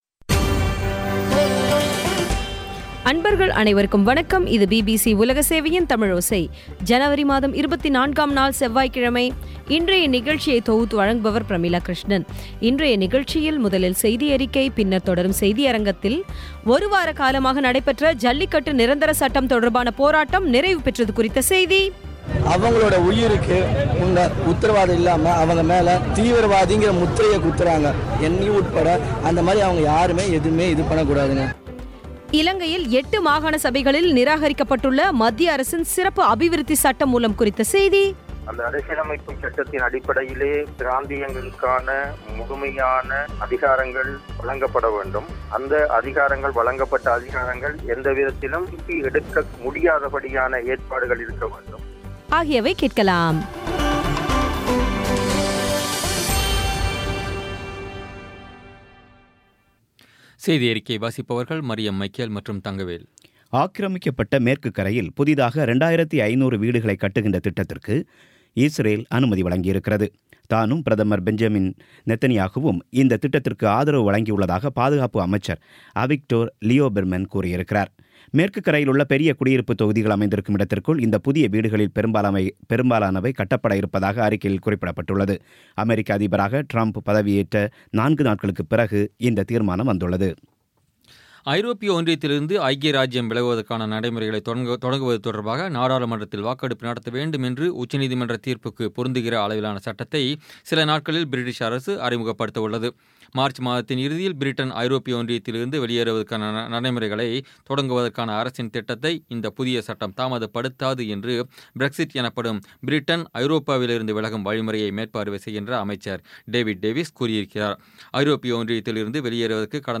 இன்றைய தமிழோசையில், முதலில் செய்தியறிக்கை, பின்னர் தொடரும் செய்தியரங்கத்தில்,